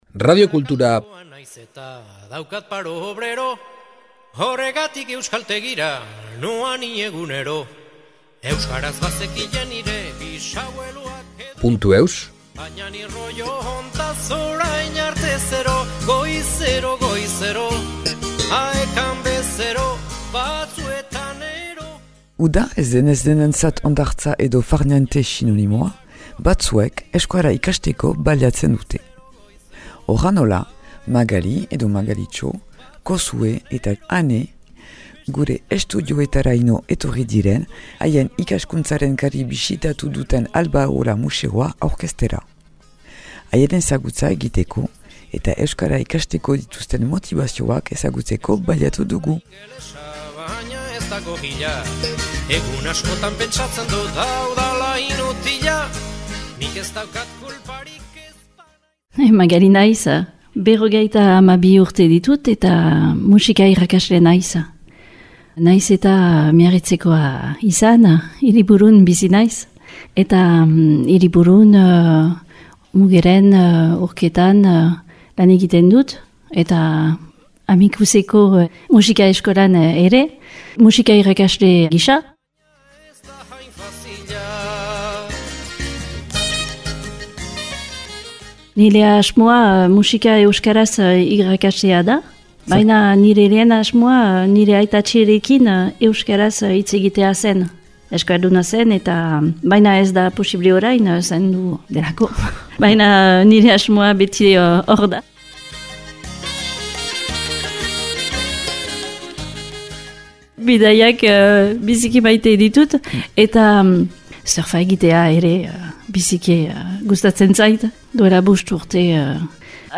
Aek-ko talde bat jin da gure estudioa bisitatzera eta nola ez, haien ezagutza egiteko eta euskara ikasteko dituzten motibazioak ezagutzeko baliatu dugu !